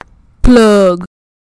plug sound
meme plug yes sound effect free sound royalty free Memes